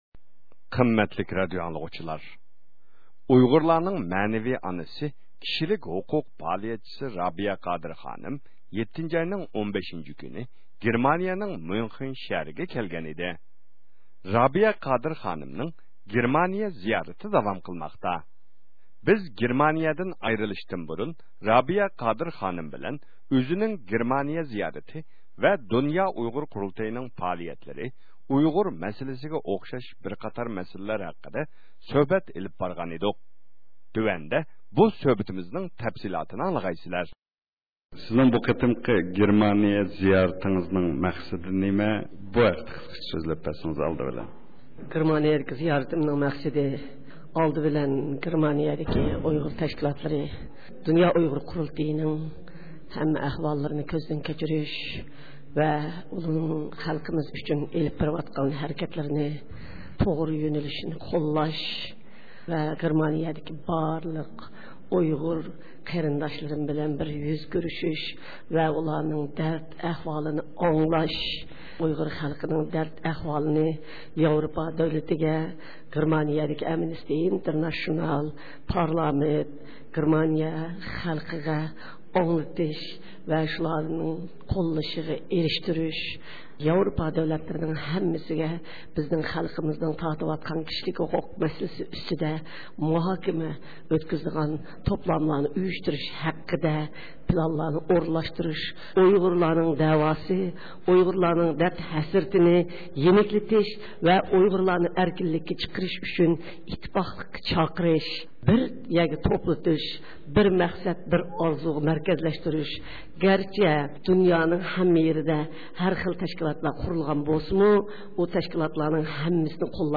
گېرمانىيە سەپىرى مۇناسىۋىتى بىلەن رابىيە قادىر خانىمنى زىيارەت – ئۇيغۇر مىللى ھەركىتى